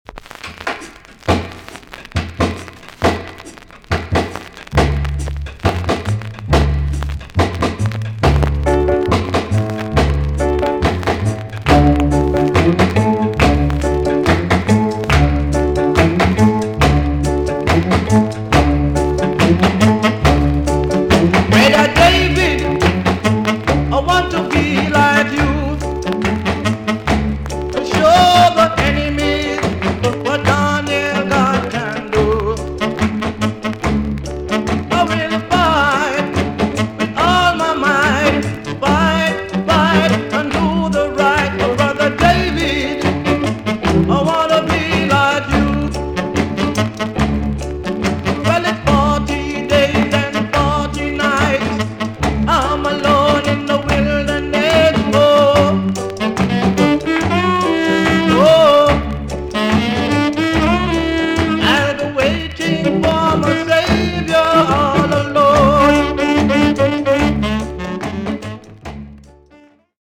TOP >SKA & ROCKSTEADY
VG ok 全体的に軽いチリノイズが入ります。